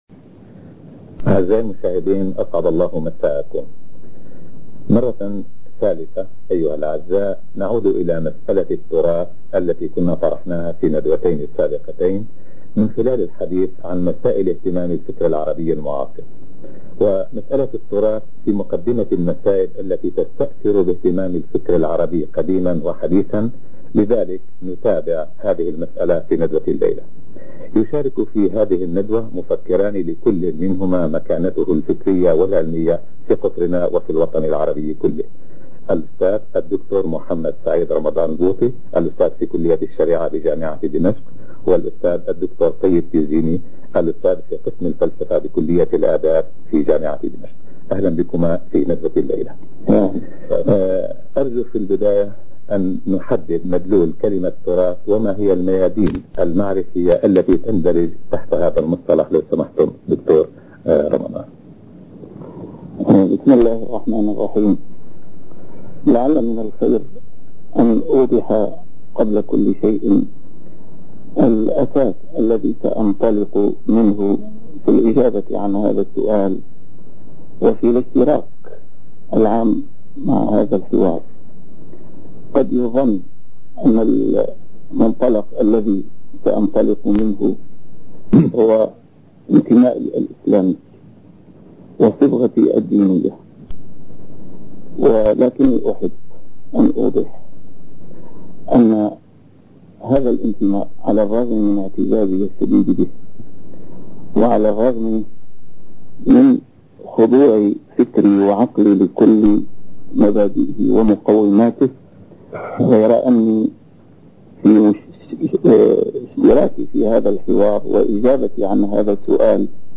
A MARTYR SCHOLAR: IMAM MUHAMMAD SAEED RAMADAN AL-BOUTI - الدروس العلمية - محاضرات متفرقة في مناسبات مختلفة - مناظرة الإمام البوطي مع الدكتور الطيب التيزيني حول التراث - تسجيل نادر